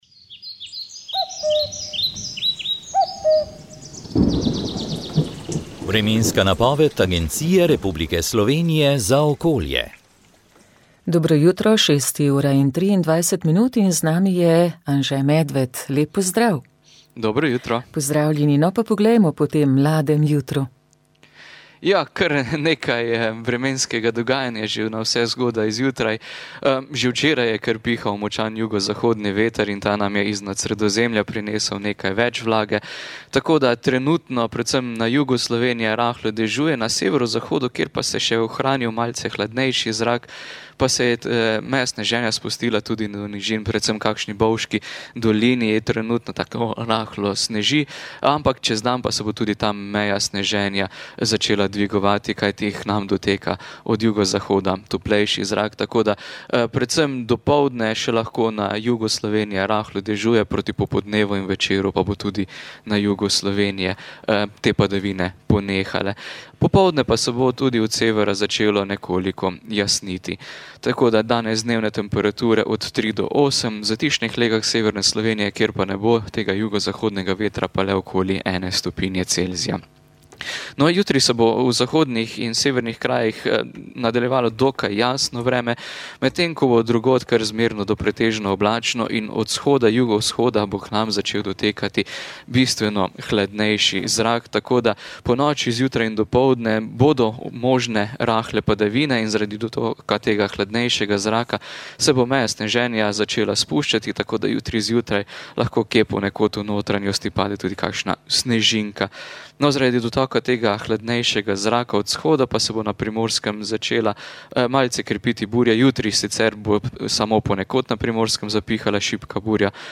Na mednarodni dan gora (obeležujemo ga od leta 2003) smo na daljavo gostili nekaj sogovornikov, ki so tako s strokovnega kot osebnega vidika poudarili pomen gorskega sveta.